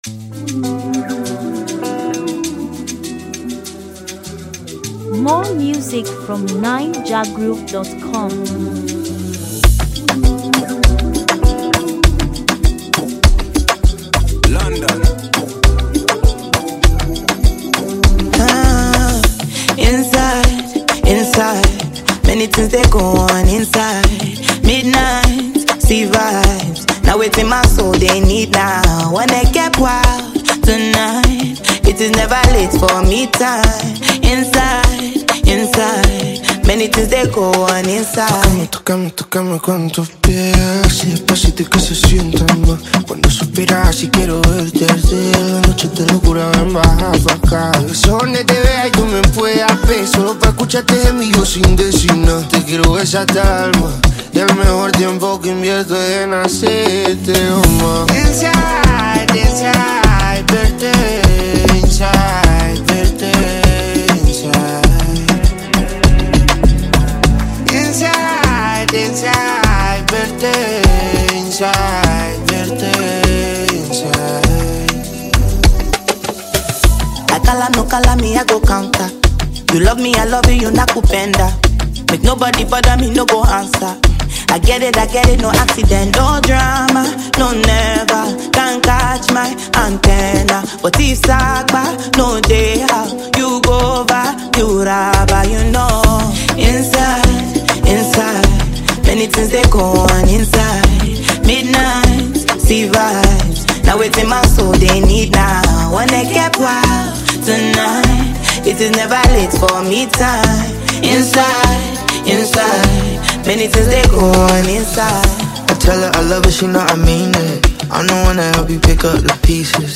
Latest, Naija-music